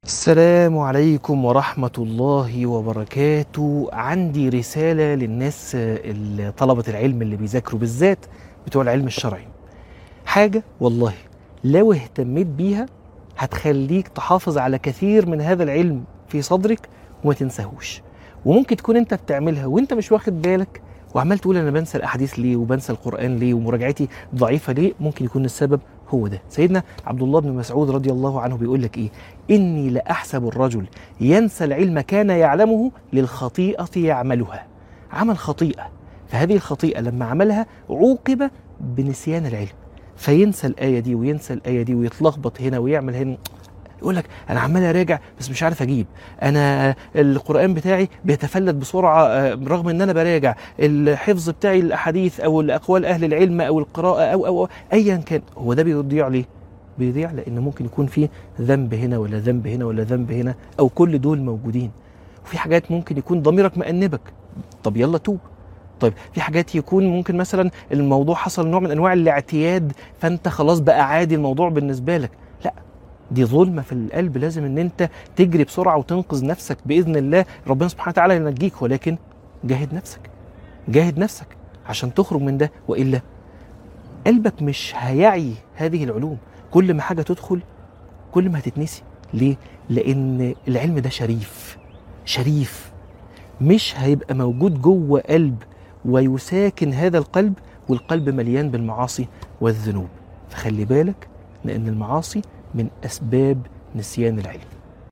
عنوان المادة ليه بننسى اللي بنذاكره؟من الحرم